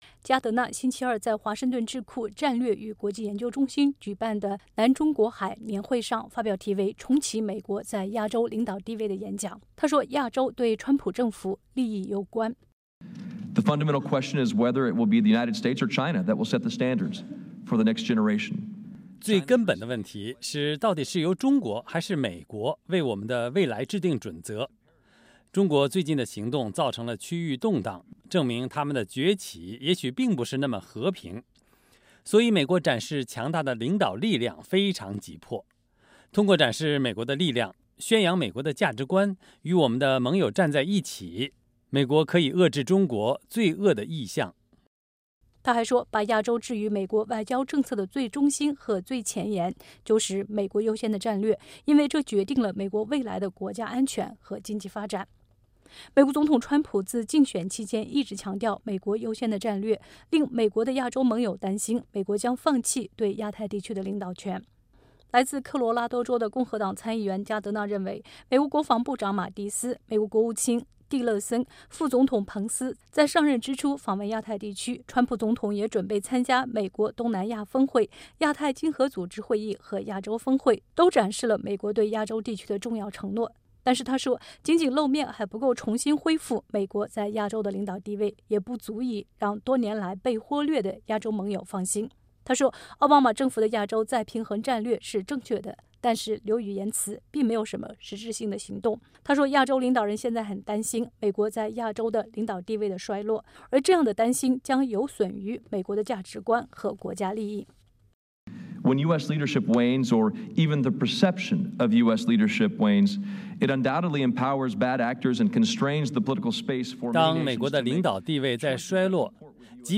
加德纳参议员在战略与国际研究中心发表讲话，谈美国在亚洲的领导权。（战略与国际研究中心视频截图，2017年7月18日）
加德纳星期二在华盛顿智库战略与研究中心举办的南中国海年会上发表题为《重启美国在亚洲领导地位》的演讲。